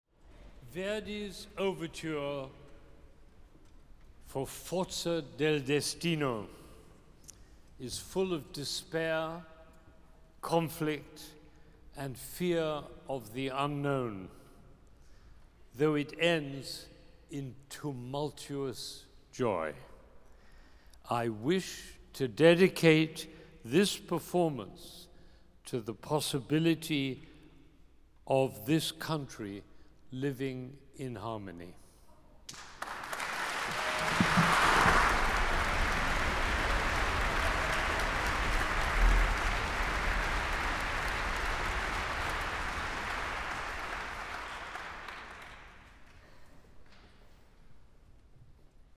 Recorded Live at Symphony Hall on November 3rd, 2024. Boston Philharmonic Youth Orchestra Benjamin Zander, conductor